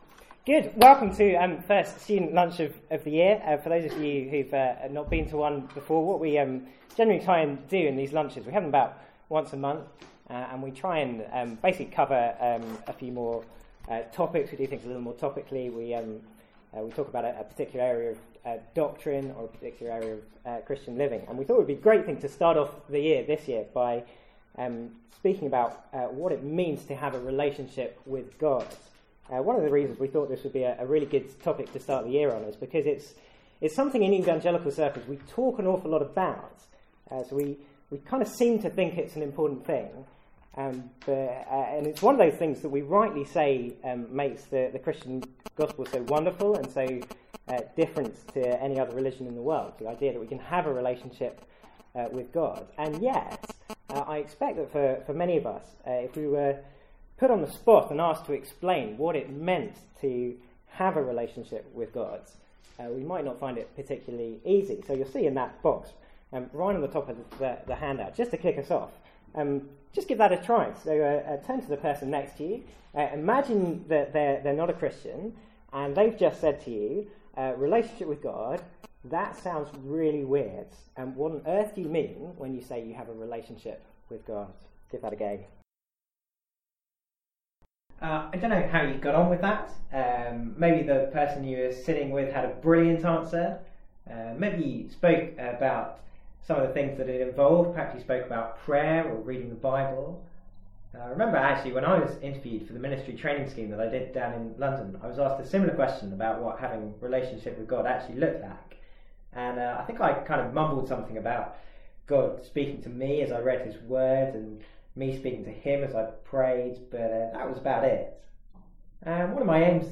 From the student lunch on 4th October 2015.